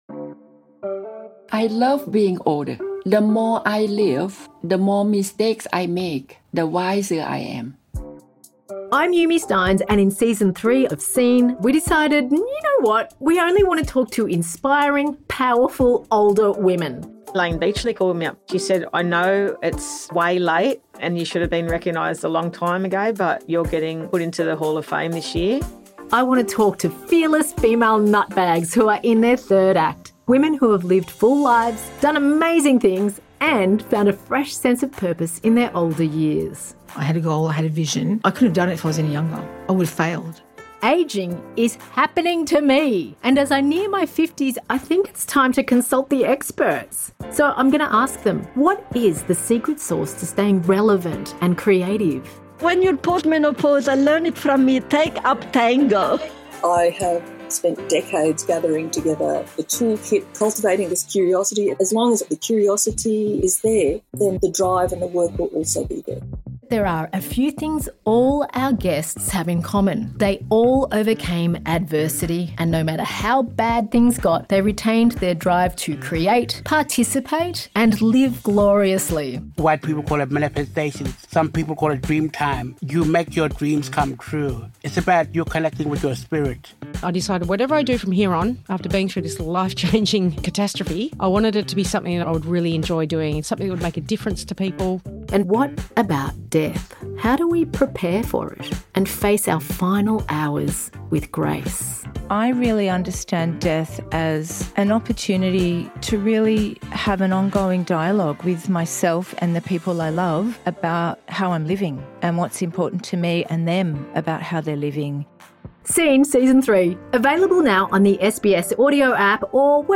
TRAILER
Host:Yumi Stynes